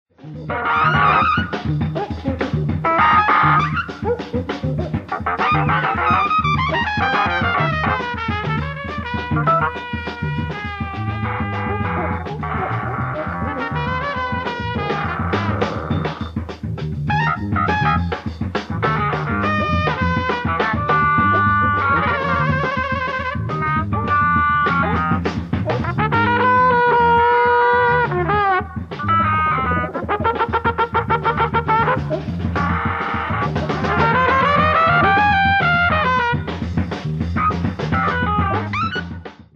LIVE AT FILLMORE WEST, SAN FRANCISCO 04/09/1970
SOUNDBOARD RECORDING